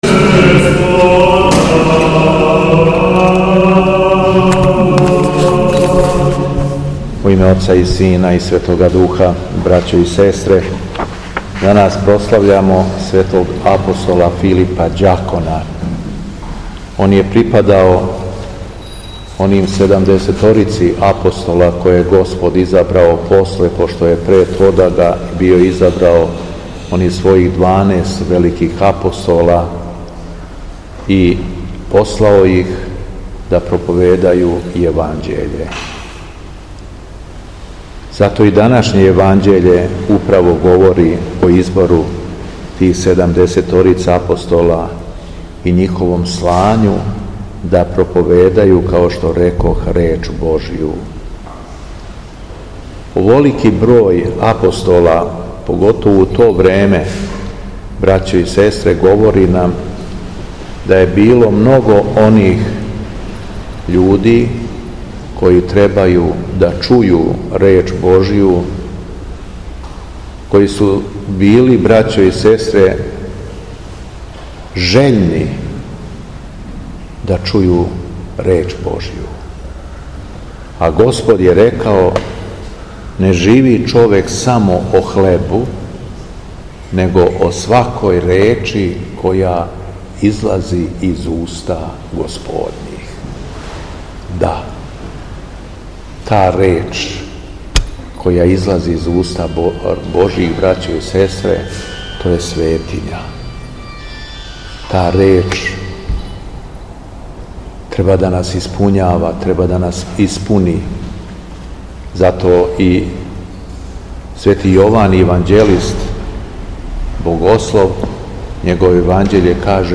Беседа Његовог Високопреосвештенства Митрополита шумадијског г. Јована
У петак, 24. октобра 2025. године, када се наша Света Црква молитвено сећа Св. апостола Филипа и Преподобног Теофана Начертаног, Његово Високопреосвештенство Митрополит шумадијски г. Јован служио је Свету Архијерејску Литургију у храму Свете Петке у крагујевачком насељу Виногради уз саслужење братства овога светога храма.
После прочитаног јеванђелског зачала Високопреосвећени Митрополит се обратио беседом сабраном народу рекавши: